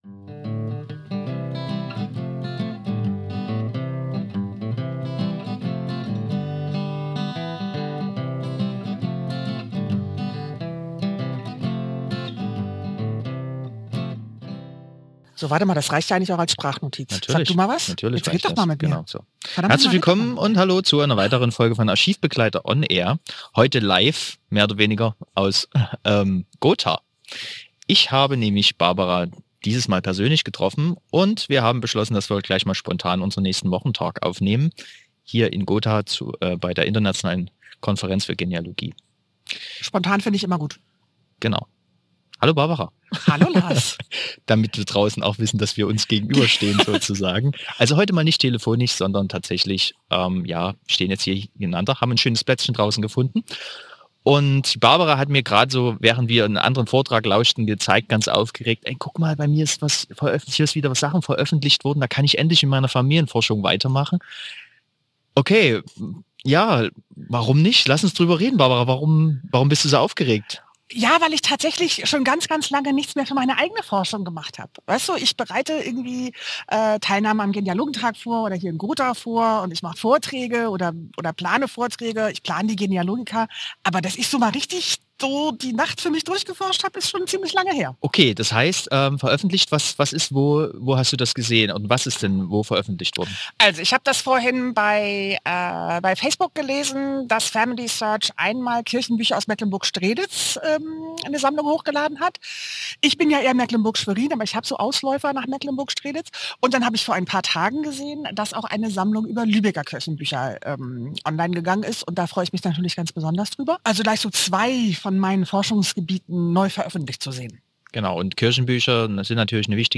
In dieser spontanen Wochentalk-Ausgabe treffe ich sie persönlich in Gotha.